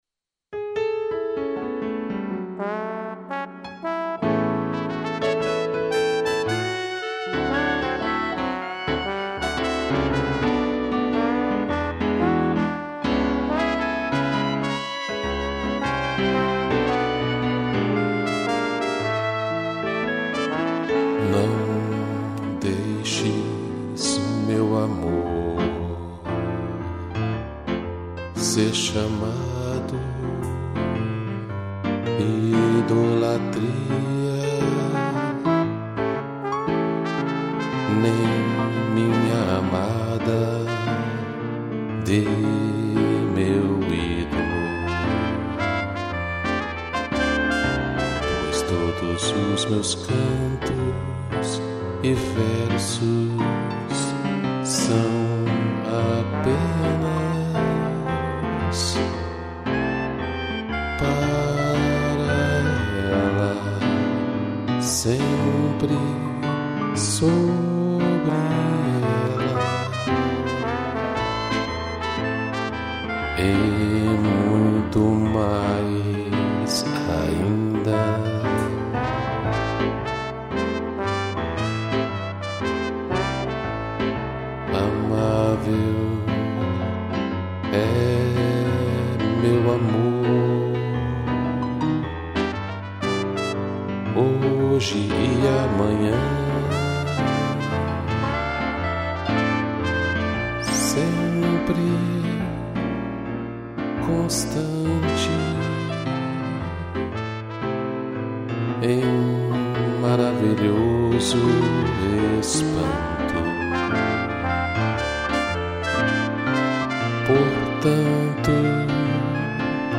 2 pianos, trombone, trompete e clarinete